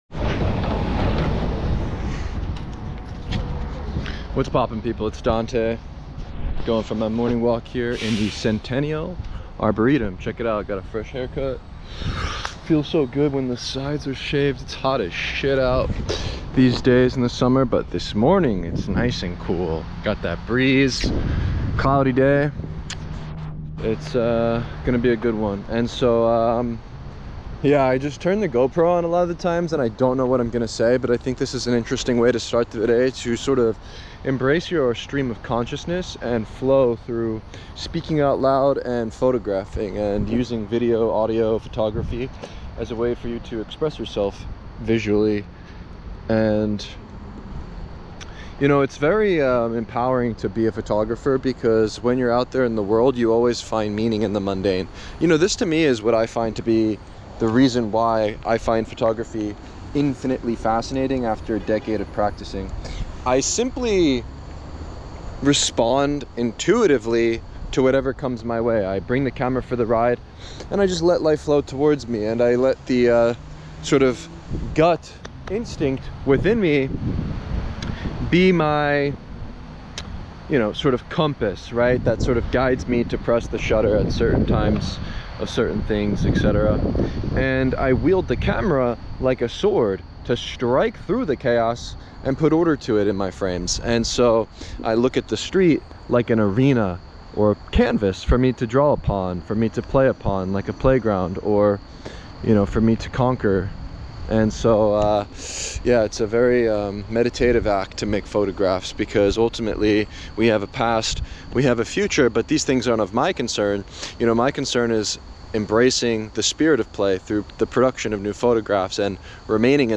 I turned the GoPro on like I usually do.
No script.
Stream of consciousness.